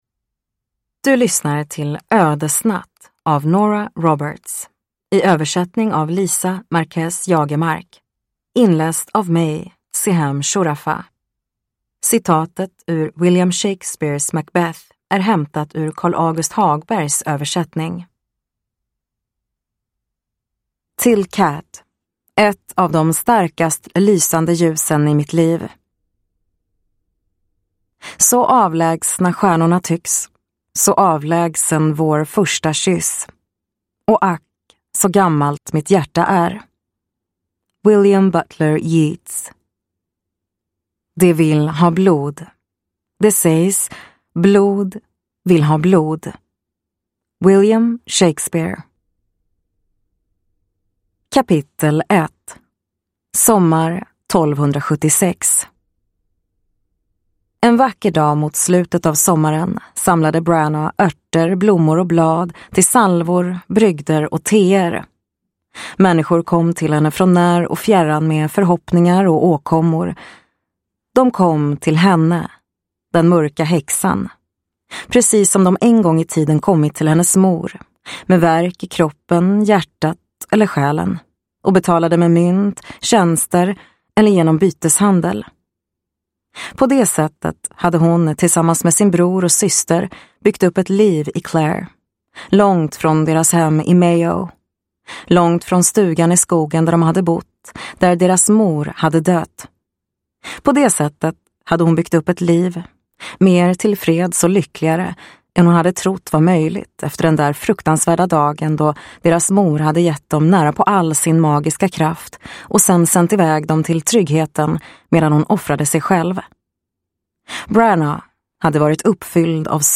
Blood Magick (svensk utgåva) – Ljudbok